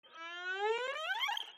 Erhu harmonic model sinusoids sms-tools sound effect free sound royalty free Memes